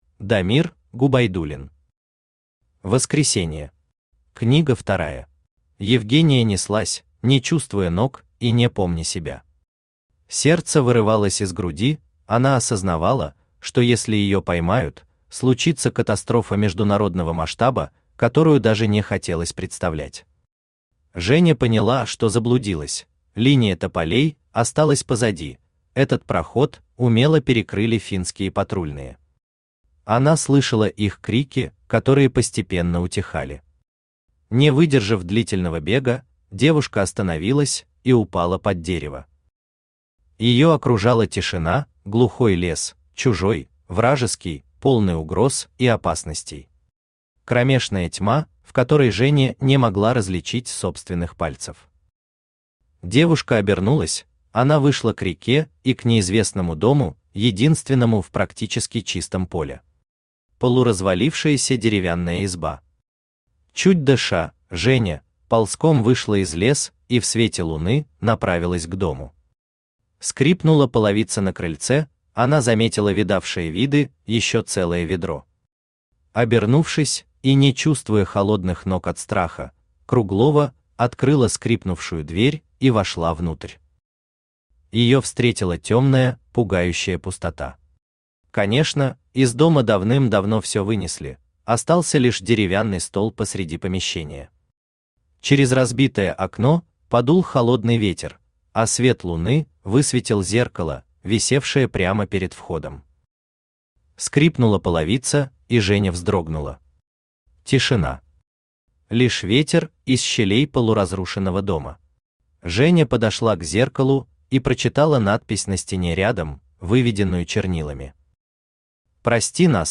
Аудиокнига Воскресенье. Книга вторая | Библиотека аудиокниг
Aудиокнига Воскресенье. Книга вторая Автор Дамир Губайдуллин Читает аудиокнигу Авточтец ЛитРес.